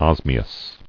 [os·mi·ous]